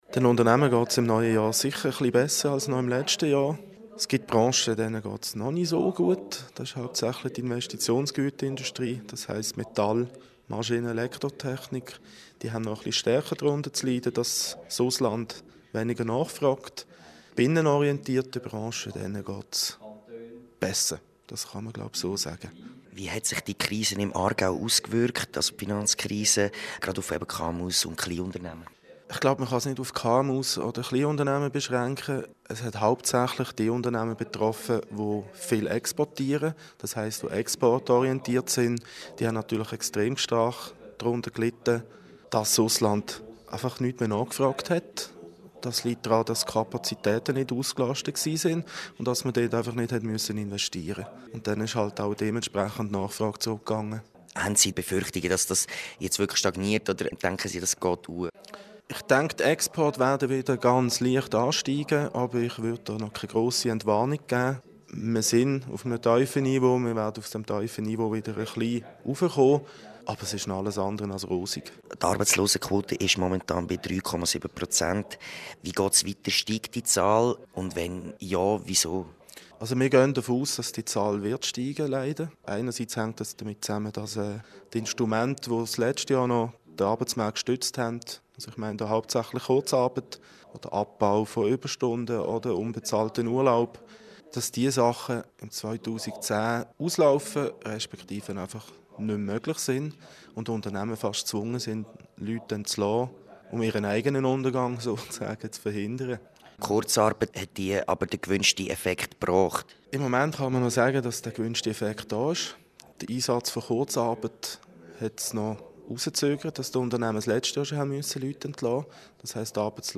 Interview zur AIHK-Wirtschaftsumfrage 2010 (MP3)